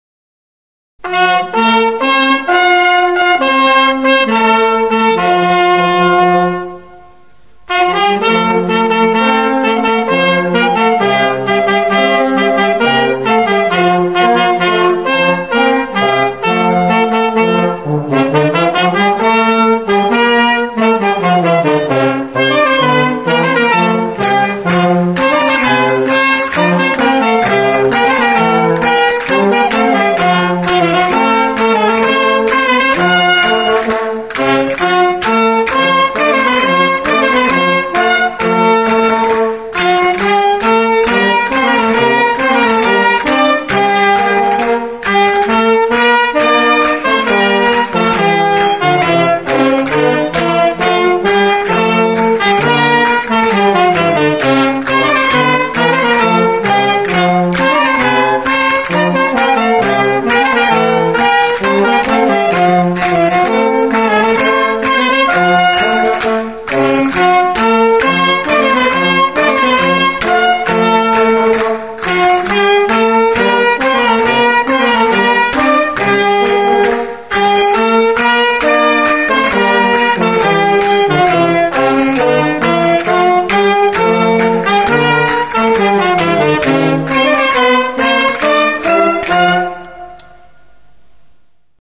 Certament, aquesta composició és l’única que segueix l’estructura típica de qualsevol bolero.
Bolero_1.mp3